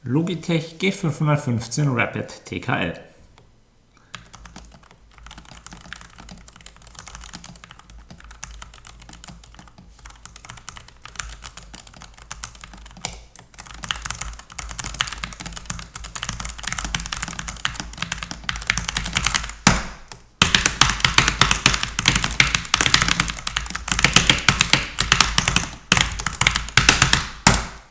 Flach heißt leise, das gilt bei der G515 erneut.
Logitech G515 Rapid TKL (Analoge Low Profile Switch)
Die Tastatur klingt mit analogen Tastern besser als mit Kailh-Switches. Unterschiede sind klar auszumachen: Das Rapid-Modell hallt weniger und produziert präzisere Anschläge, wobei sie ein wenig satter und tiefer „klackt“. Stabilisatoren der Enter- und Rücktaste sind hingegen ein kleiner Rückschritt, von ihnen ertönt beim Betätigen ein deutliches Klicken.